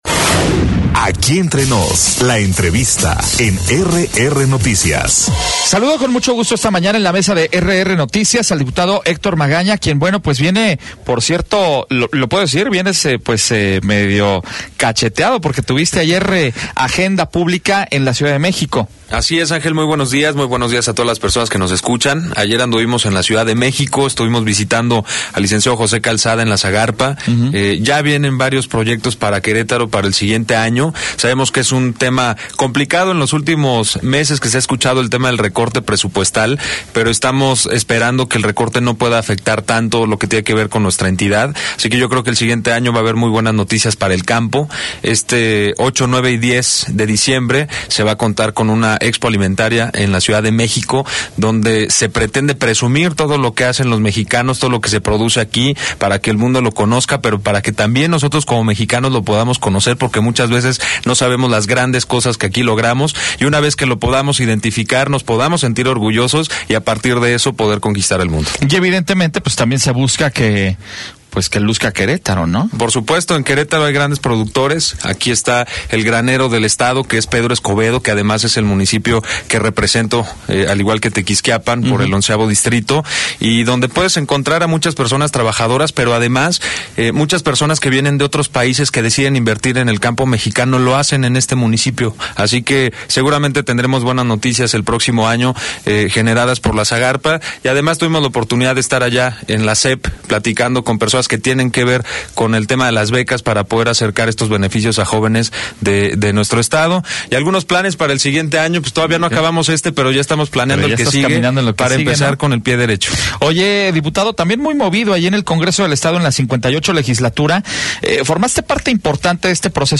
Entrevista al diputado Héctor Magaña - RR Noticias